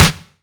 kits/RZA/Snares/WTC_SNR (76).wav at 32ed3054e8f0d31248a29e788f53465e3ccbe498